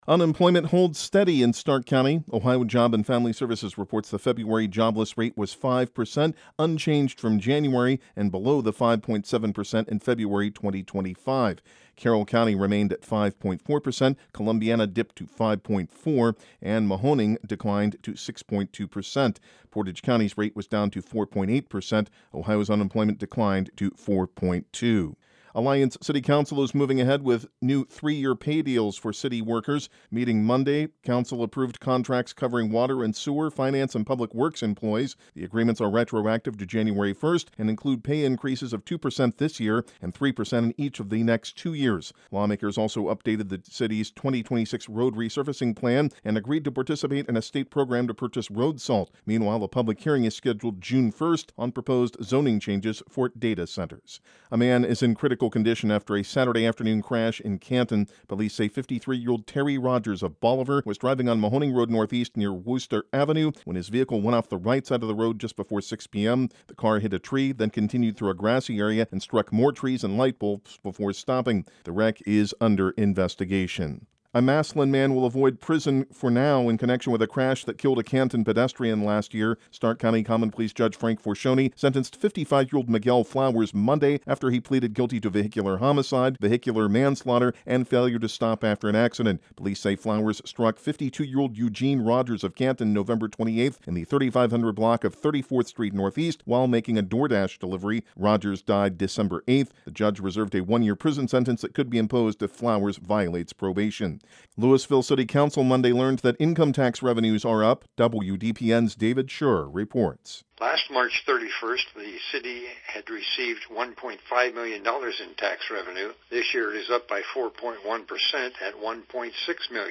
Afternoon News